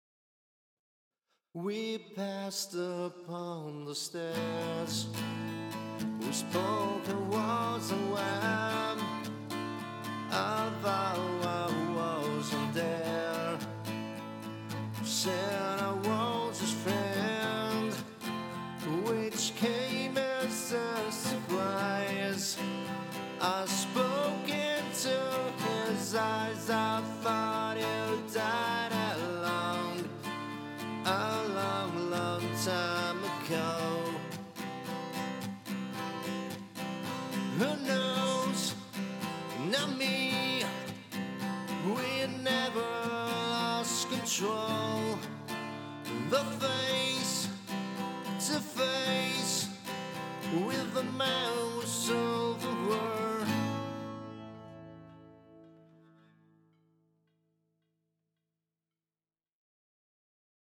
Gtr_Vox_4KOff.mp3